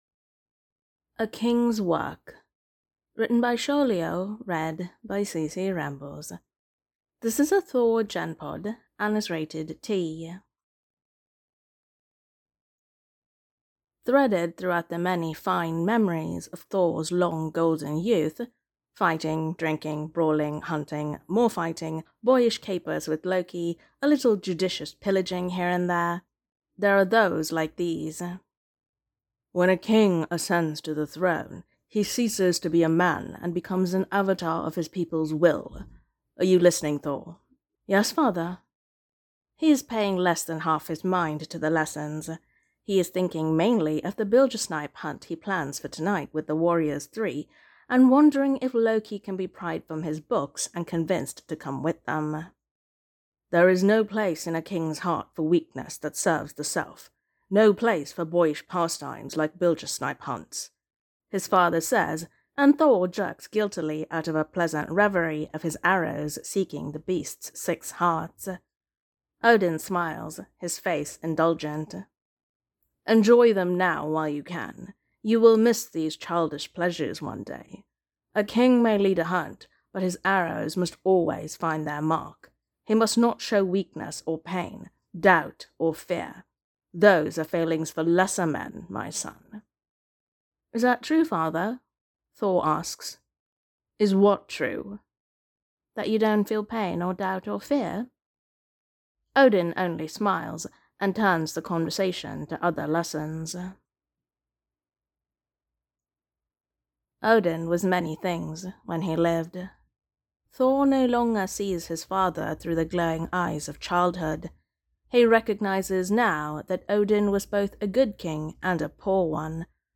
[Podfic] A King's Work